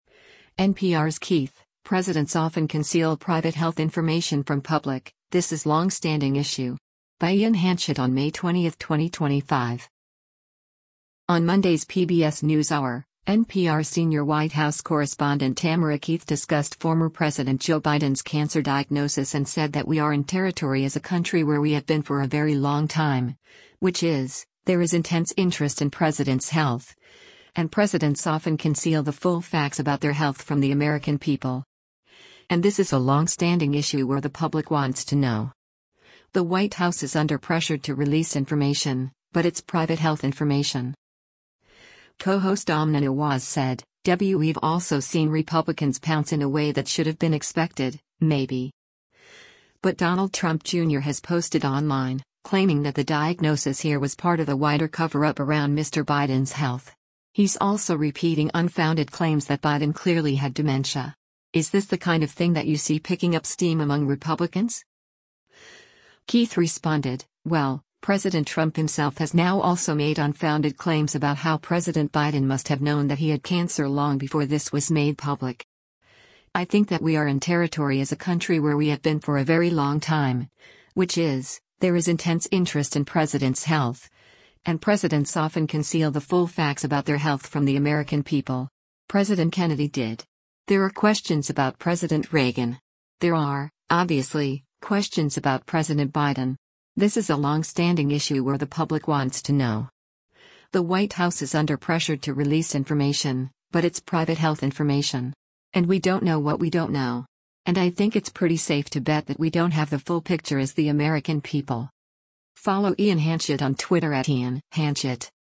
On Monday’s “PBS NewsHour,” NPR Senior White House Correspondent Tamara Keith discussed former President Joe Biden’s cancer diagnosis and said that “we are in territory as a country where we have been for a very long time, which is, there is intense interest in presidents’ health, and presidents often conceal the full facts about their health from the American people.”